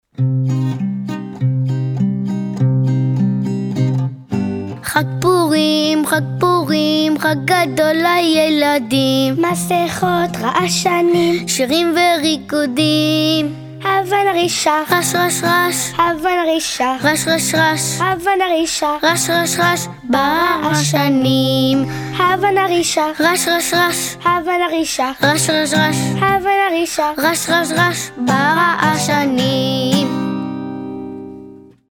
Audio Enfants: